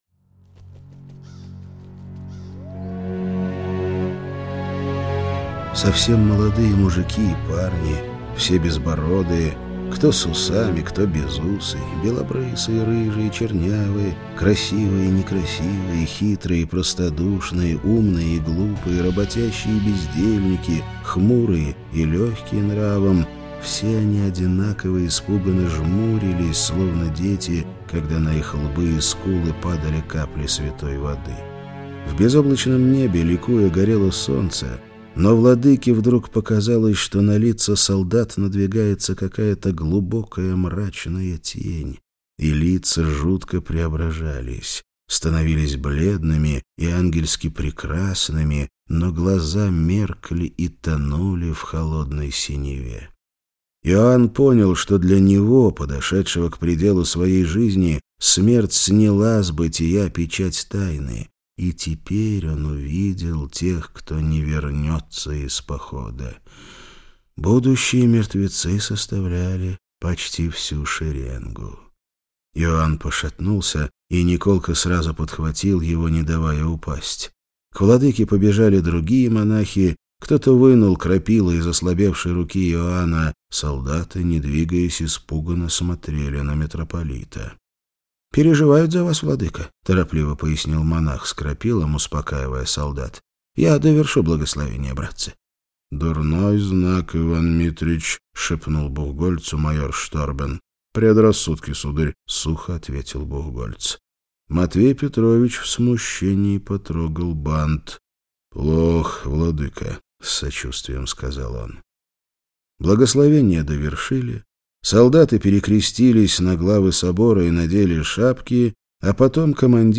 Аудиокнига Тобол. Мало избранных - купить, скачать и слушать онлайн | КнигоПоиск